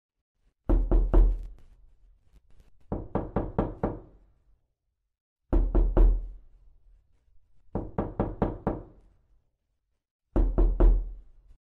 Realistic Door
realistic-door.mp3